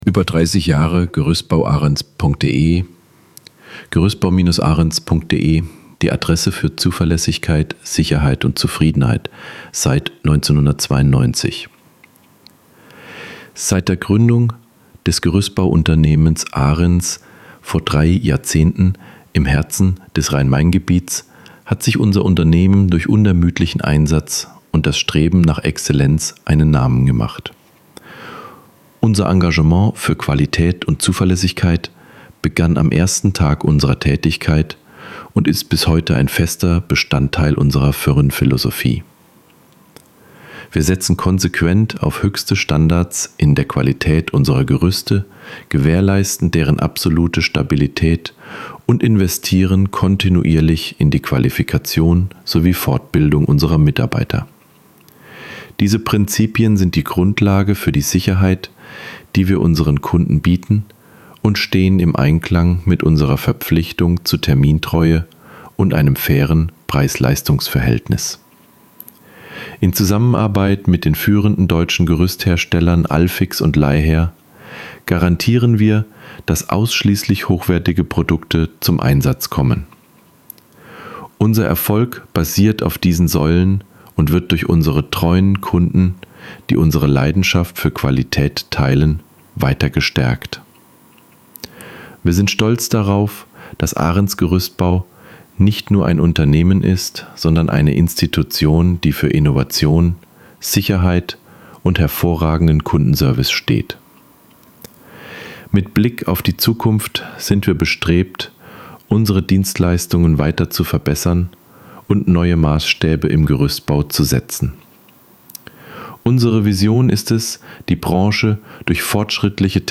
Text vorlesen – über die Geschichte von Gerüstbau Arens